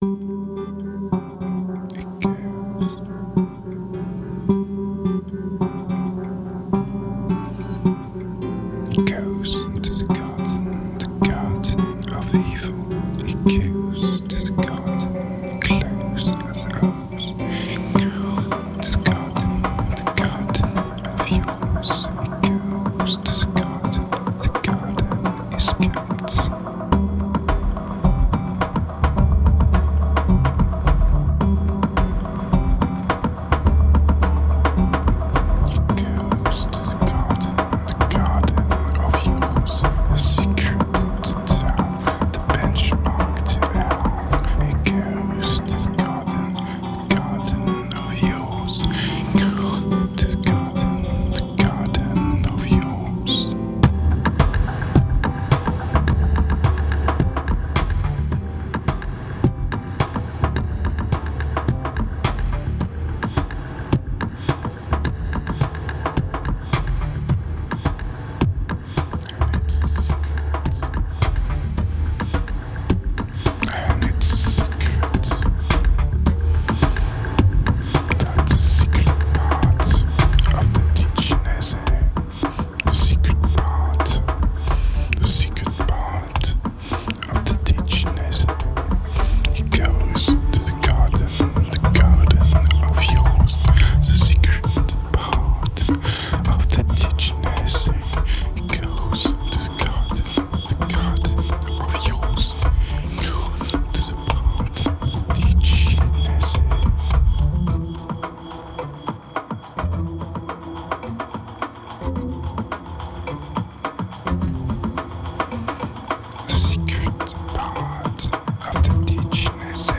2487📈 - 51%🤔 - 107BPM🔊 - 2009-08-23📅 - 54🌟